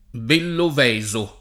Belloveso [ bellov $@ o ]